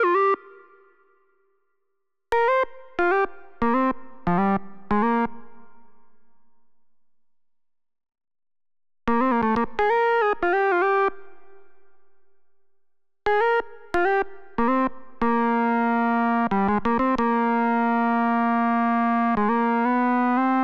09 lead A2.wav